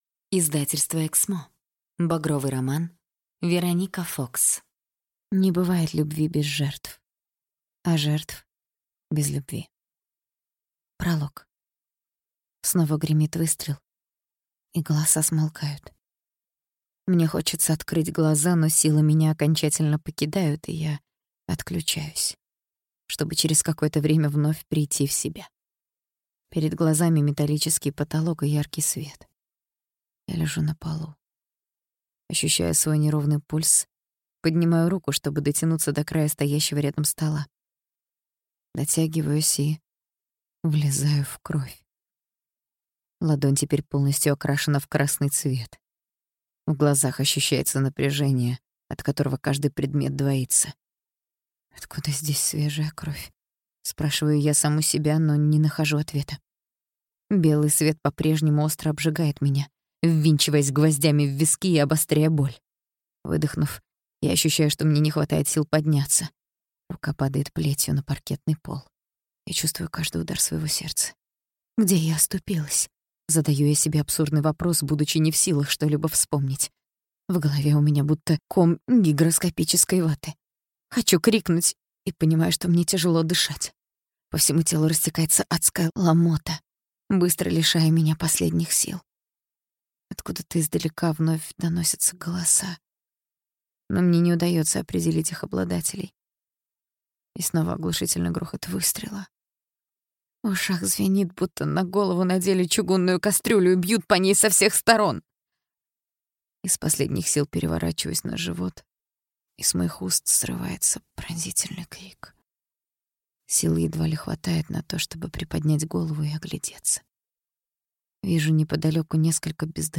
Аудиокнига Багровый роман | Библиотека аудиокниг